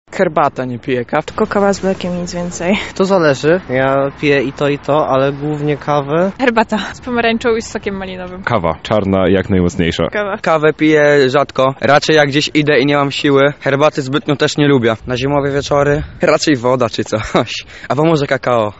[SONDA] Na zimowe chłody najlepsza jest kawa czy herbata?
Zapytaliśmy mieszkańców Lublina o to, jaki napój wybierają zimą.